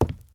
PixelPerfectionCE/assets/minecraft/sounds/step/wood3.ogg at mc116
wood3.ogg